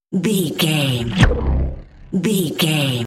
Sci fi pass by insect wings fast
Sound Effects
Fast
futuristic
pass by